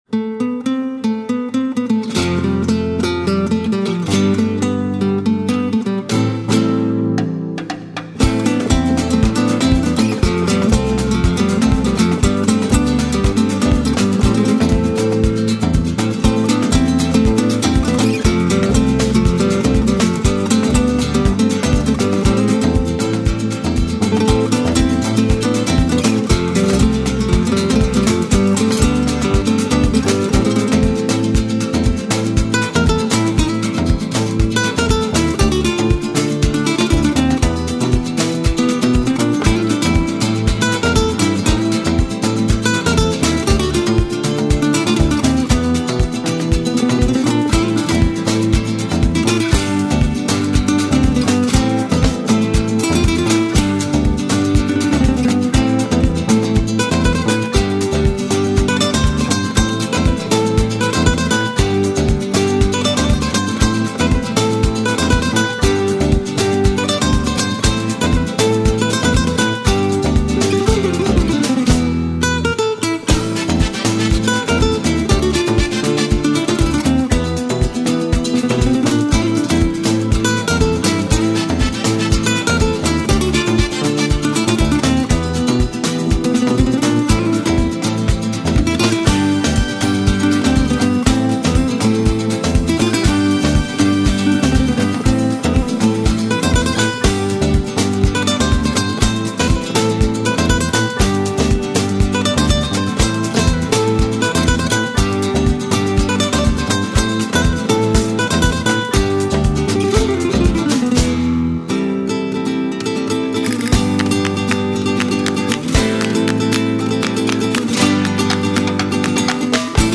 由五兄弟组成的弗拉门哥吉他乐队动感录音